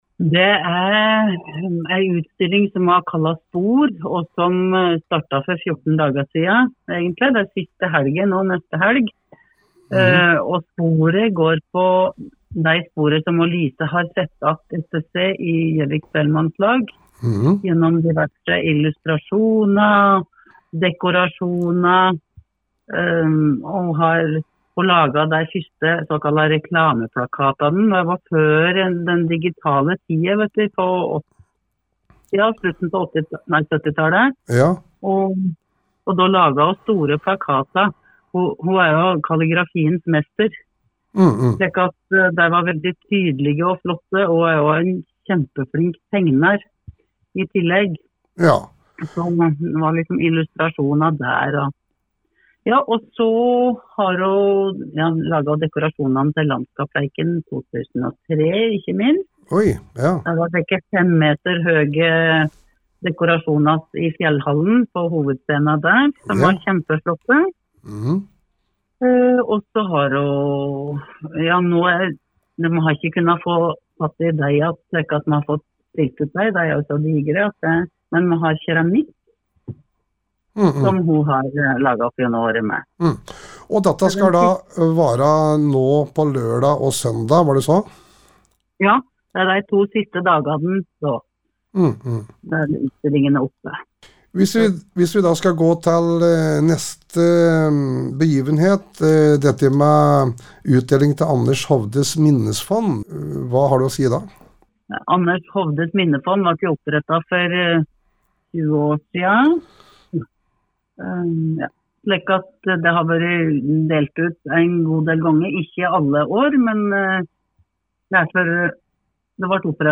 Innslag